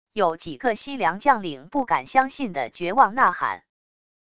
有几个西凉将领不敢相信的绝望呐喊wav音频